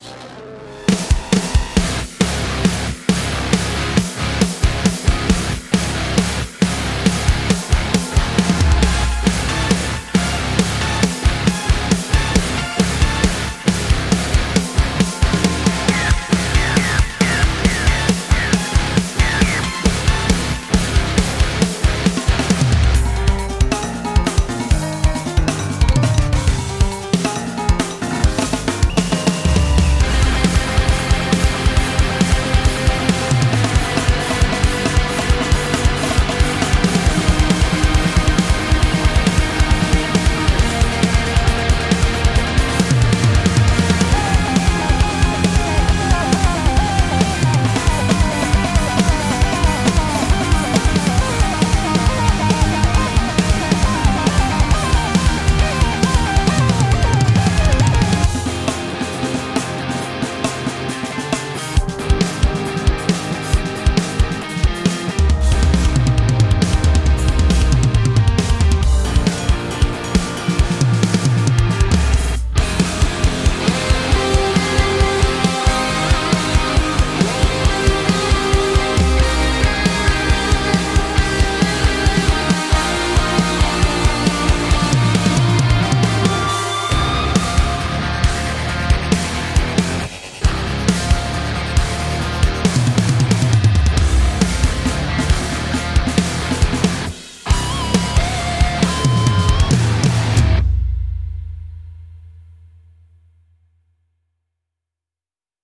Описание: Ударная установка